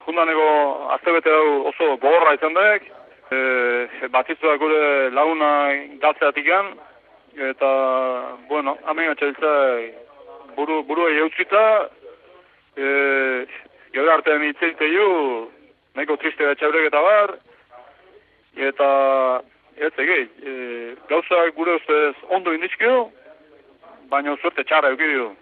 Euskadi Irratian egindako elkarrizketa zatia